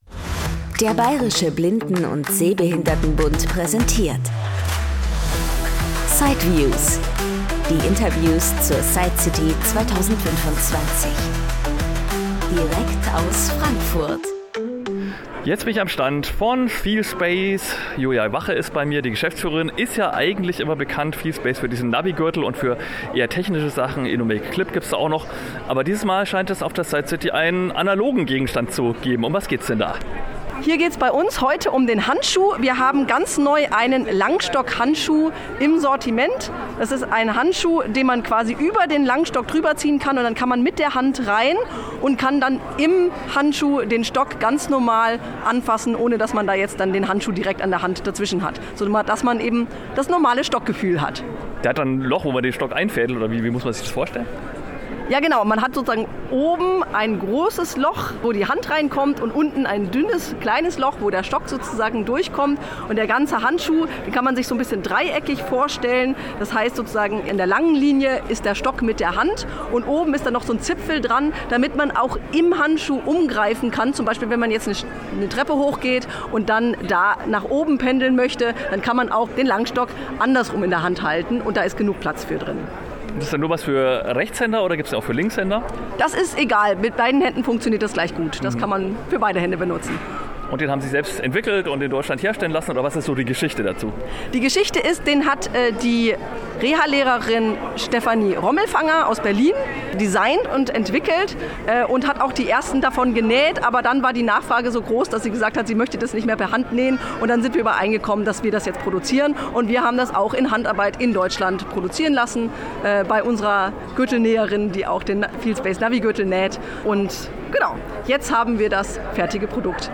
gibt’s im Gespräch direkt von der Messe.